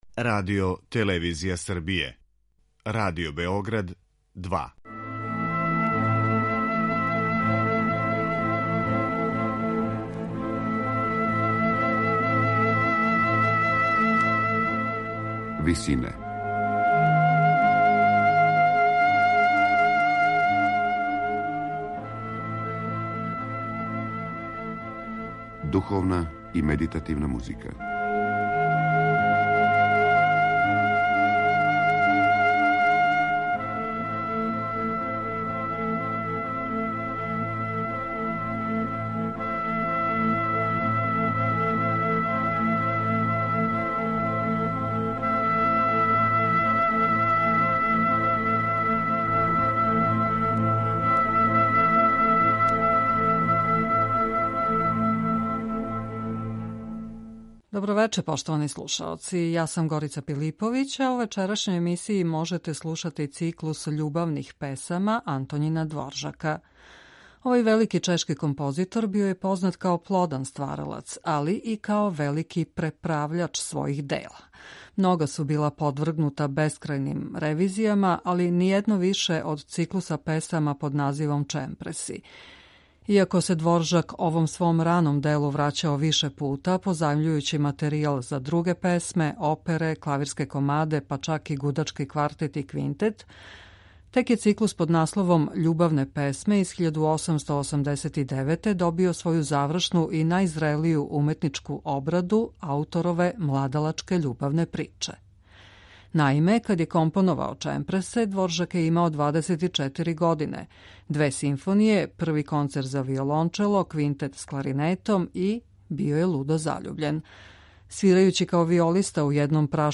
У вечерашњој емисији Висине можете слушати циклус љубавних песама опус 83 Антоњина Дворжака.